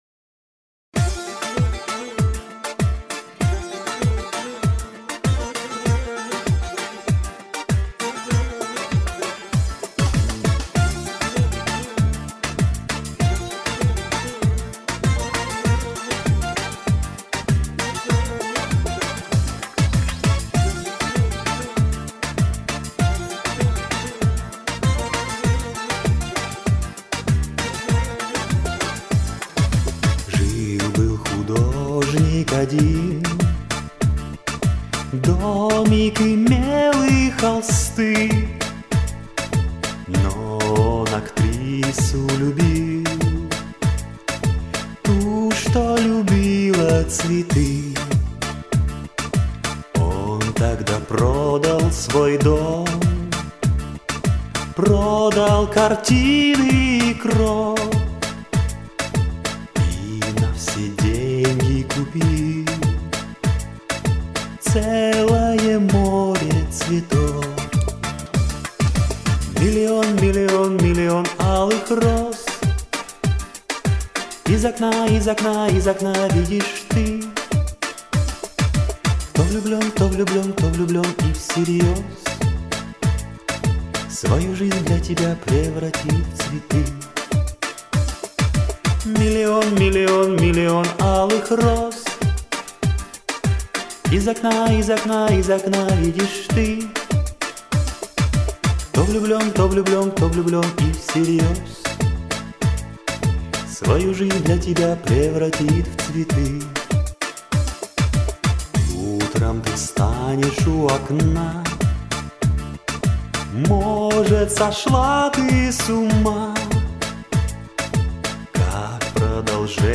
Я отдаю предпочтение мужской версии 2-3!